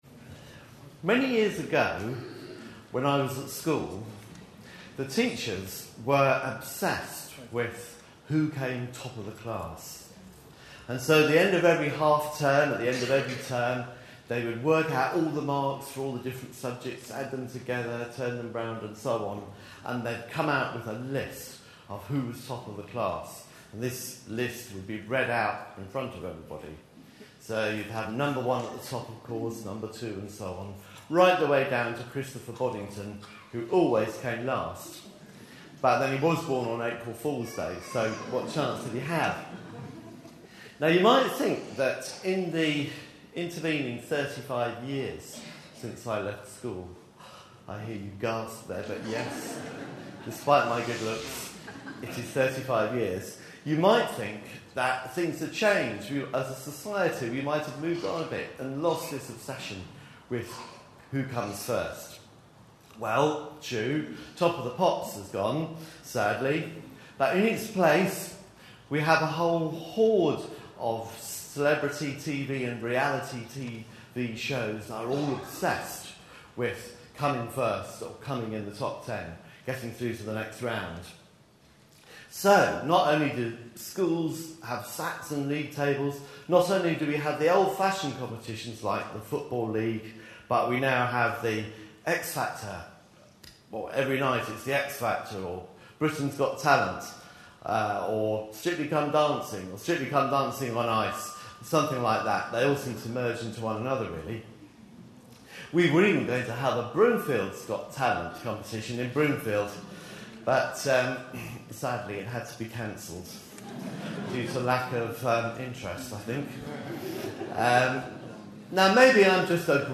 A sermon preached on 20th May, 2012, as part of our Family Service - The Ten Commandments series.